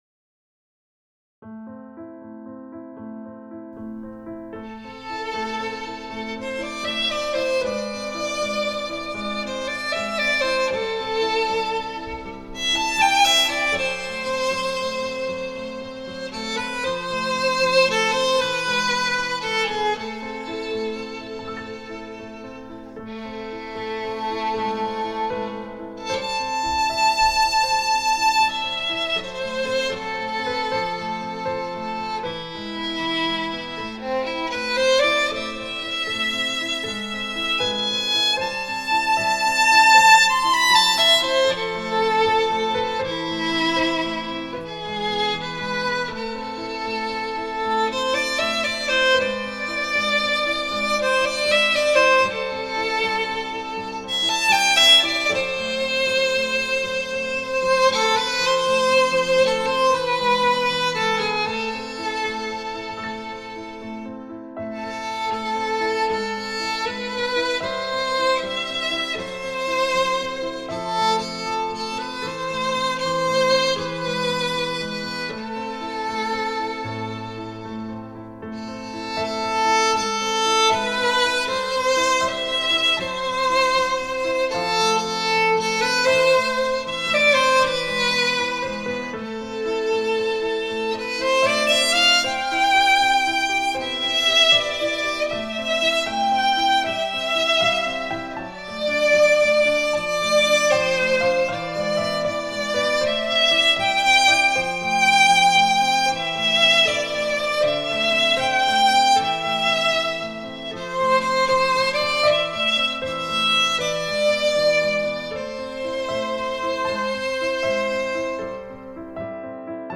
Instrumentation: Violin and Piano
A triplet pattern is used throughout.
Violin Solo with Piano Accompaniment  or